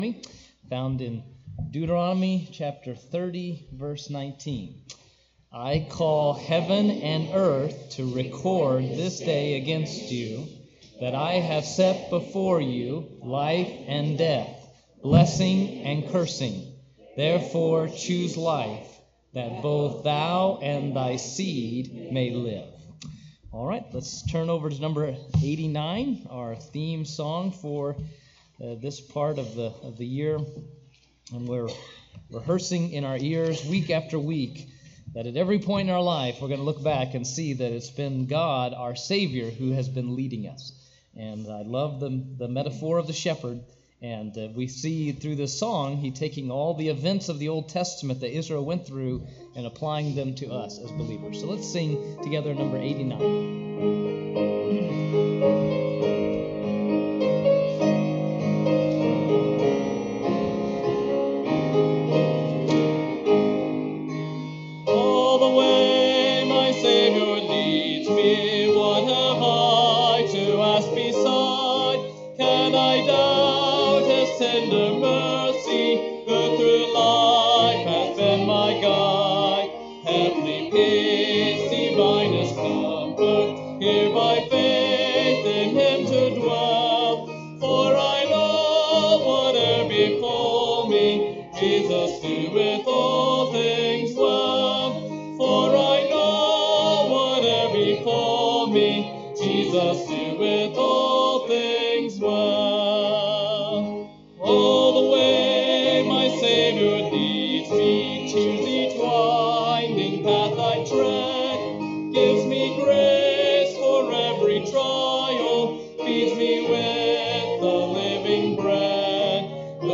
Sermons Sort By Date - Newest First Date - Oldest First Series Title Speaker Amos Sunday Evening.